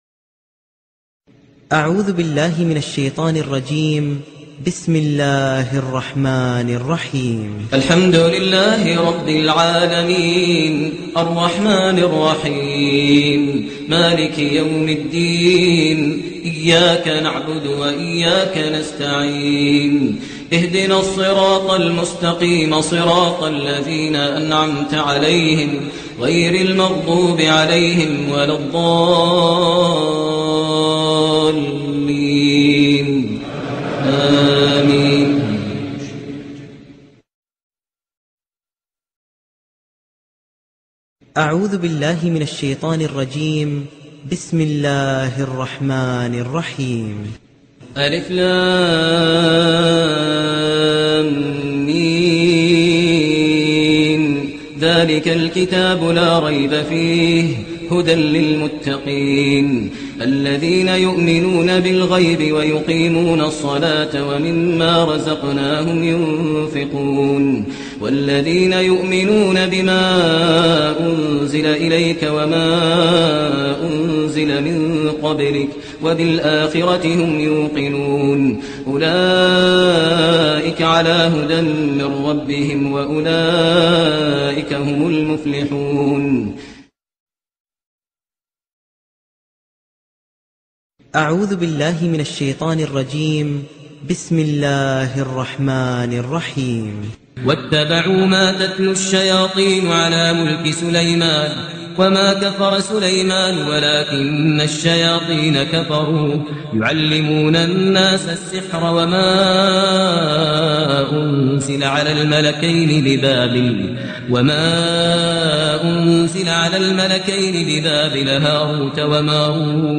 الشيخ ماهر المعيقلي الرقية الشرعية لعلاج الحسد والسحر والهم و الغم و الضيق النسخة الأصلية > تلاوات عطرة للشيخ ماهر المعيقلي > مزامير الفرقان > المزيد - تلاوات الحرمين